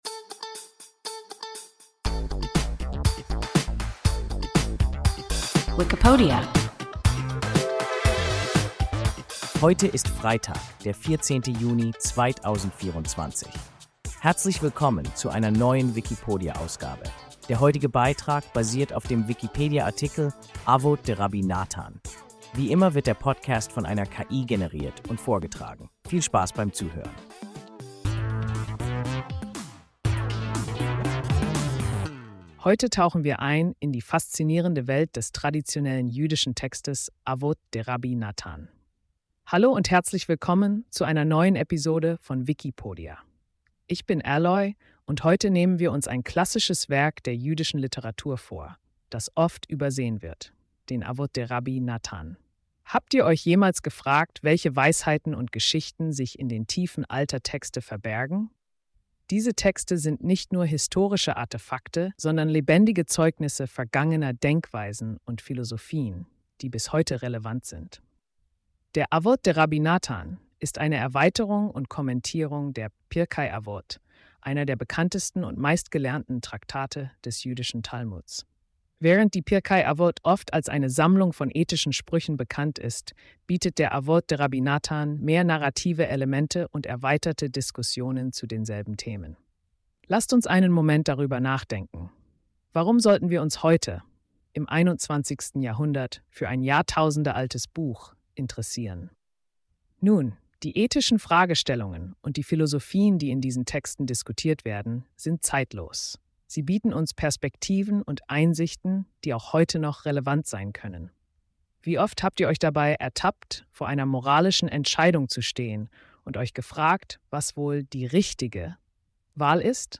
Avot de-Rabbi Nathan – WIKIPODIA – ein KI Podcast